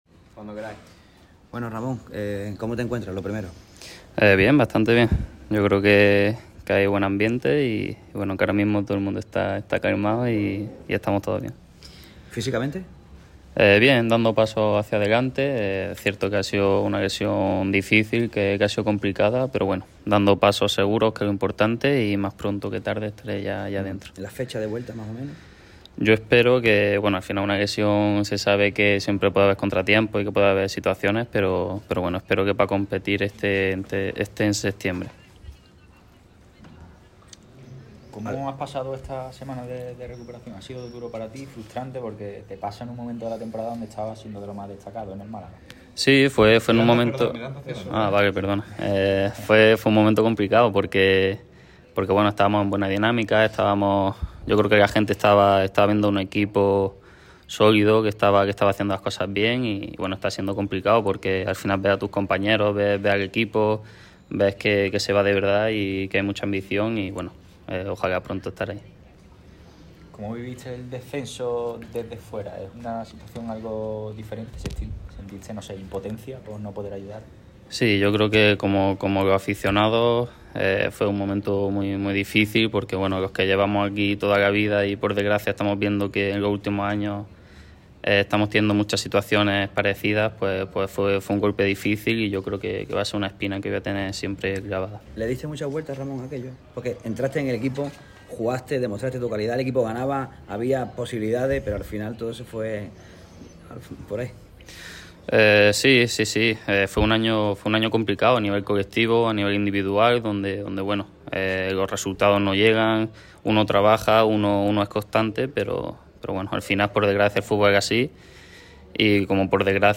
Este jueves ha tenido lugar la ofrenda floral de la plantilla malaguista a la Divina Pastora.